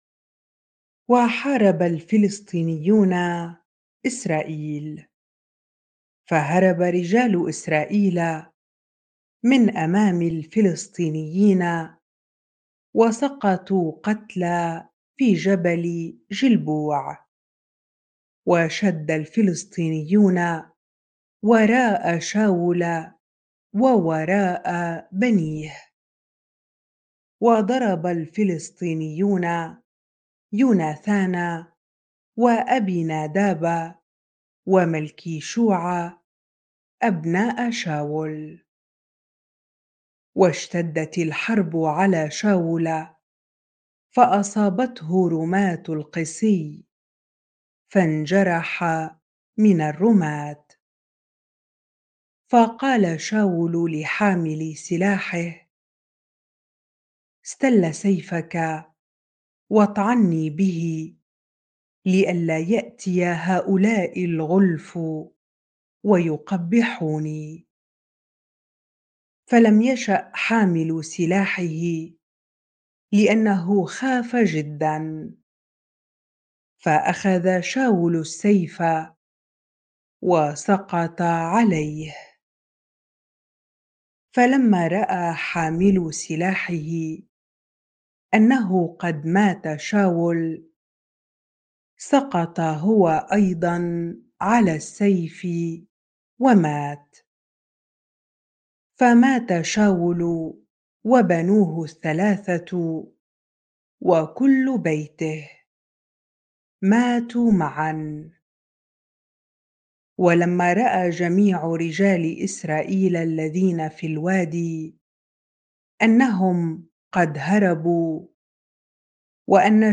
bible-reading-1 Chronicles 10 ar